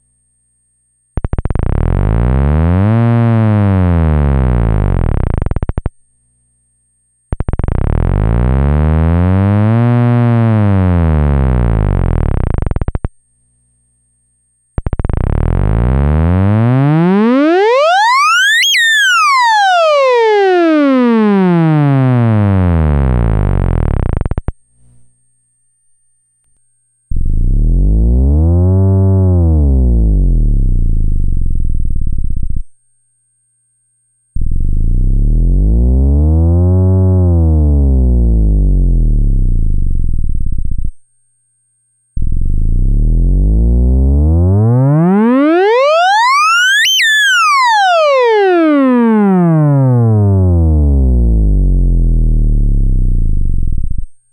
This simple mod also kills the raspiness, and I suppose that's necessarily a side effect of reduced coupling by whatever means.
The first two samples I'm holding my closed fist at zero beat, then opening and closing my hand.
The third sample is going from zero beat to touching the pitch antenna and back to zero beat.  Touching pitch antenna before the mod produced 3280Hz, and after the mod this was 3120Hz, so very little change here, and the timbre seems much the same when not near zero beat.